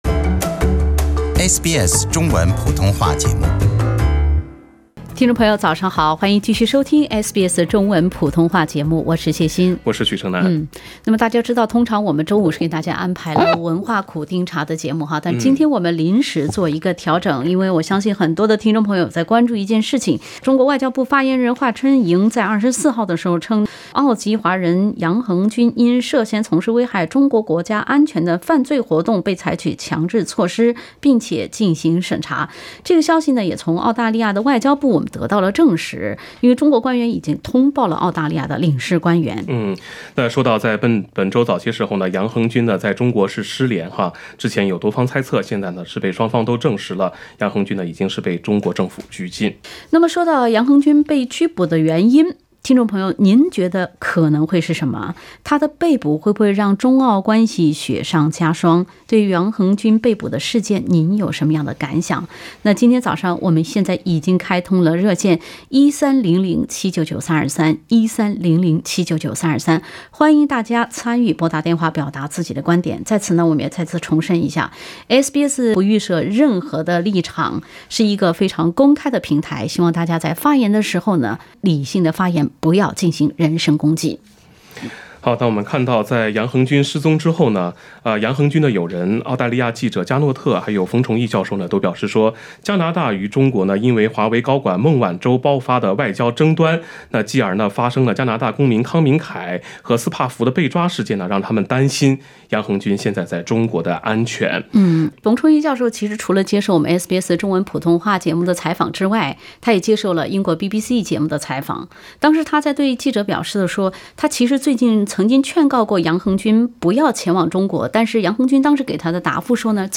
澳大利亚籍作家杨恒均在入境中国后，被采取强制措施进行审查。澳大利亚华人听众致电SBS，参与评议该事件。